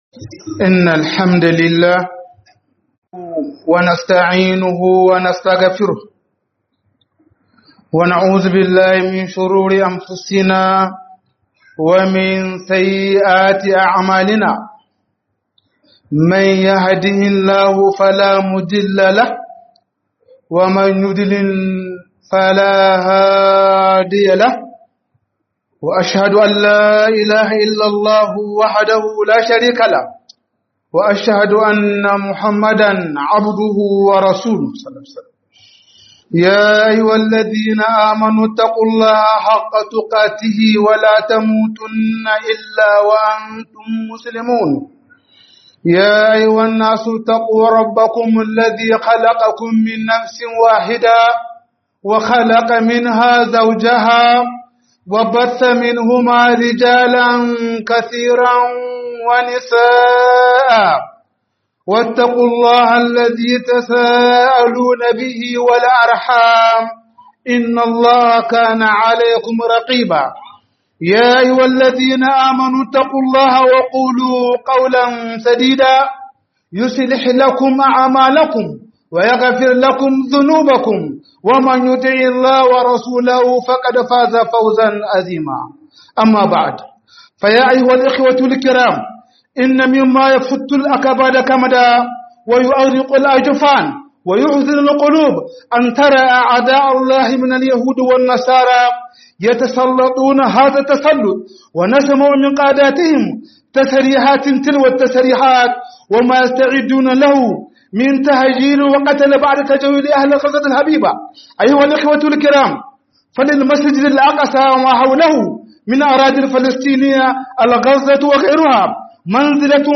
010 Qudus Tamuce Musulmai 01 - HUDUBA